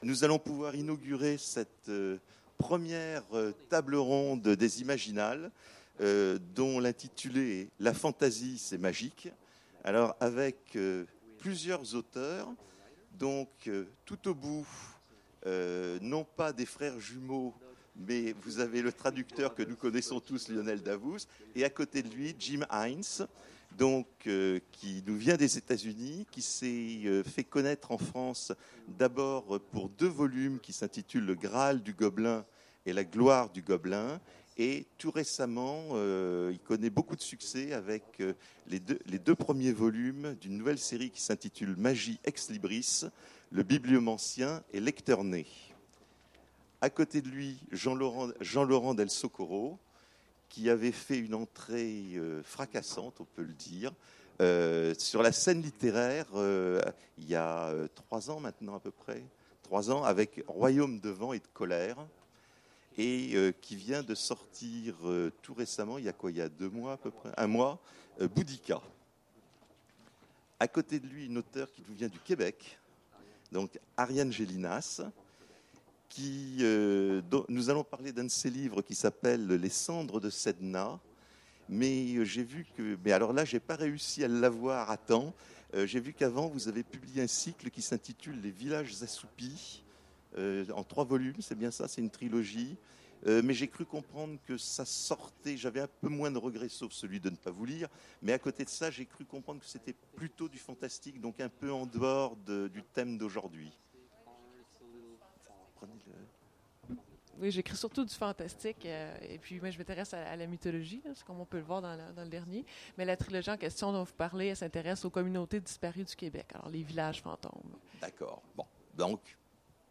Mots-clés Fantasy Magie Conférence Partager cet article